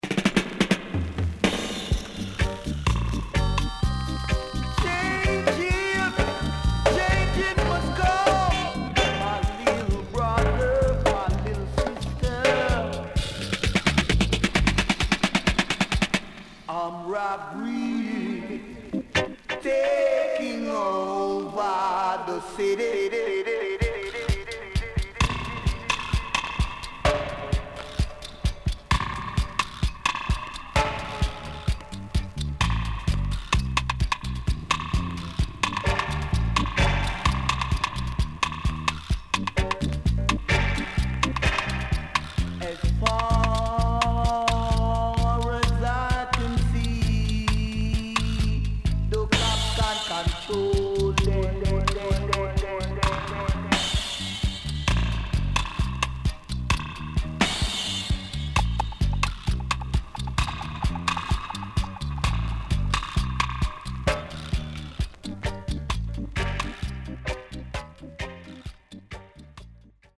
HOME > REGGAE / ROOTS  >  KILLER & DEEP  >  STEPPER
渋Stepper Roots Vocal & Dubwise
SIDE A:所々チリノイズがあり、少しプチノイズ入ります。